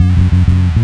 FINGERBSS5-L.wav